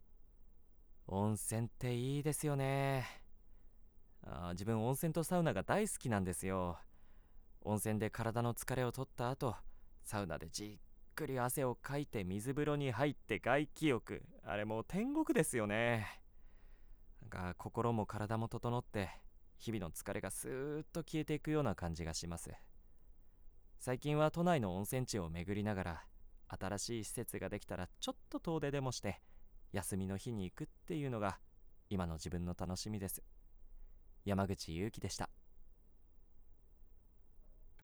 方　言　：　北海道弁
フリートーク